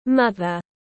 Mẹ tiếng anh gọi là mother, phiên âm tiếng anh đọc là /ˈmʌð.ər/.
Mother /ˈmʌð.ər/